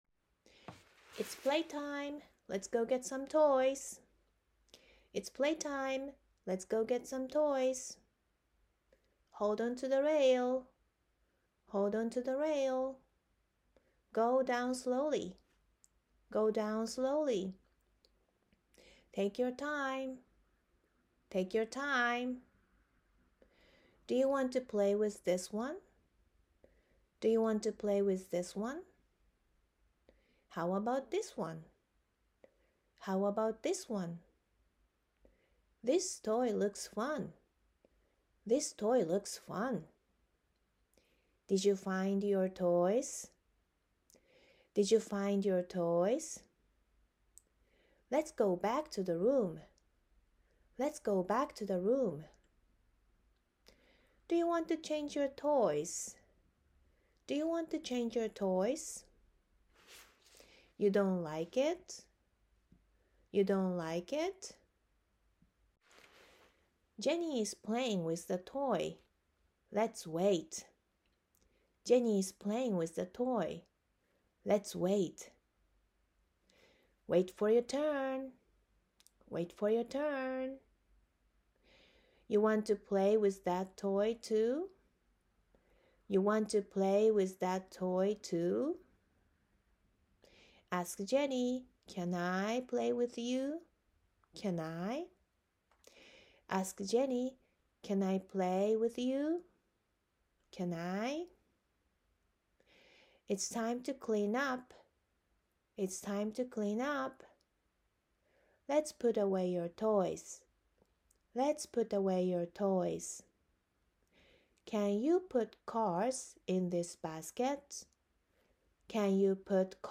音声は１文につき２回入っています。